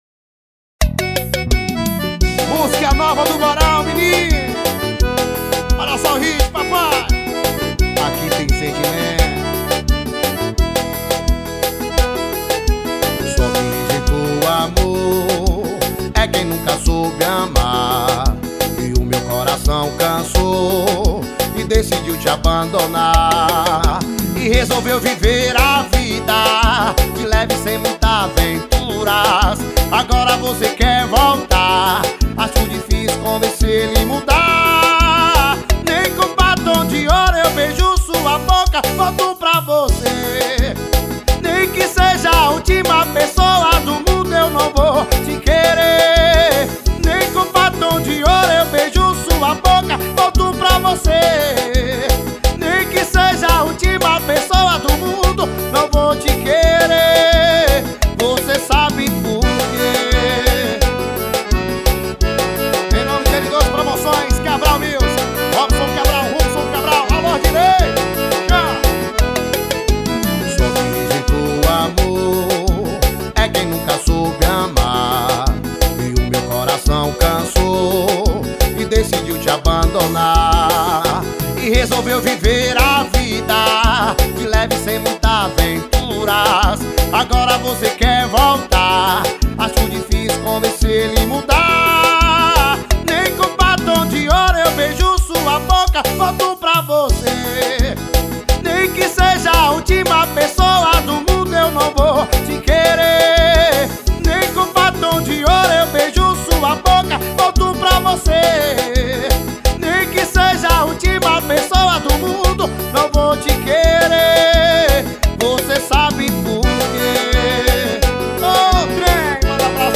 2024-11-25 21:00:28 Gênero: Sertanejo Views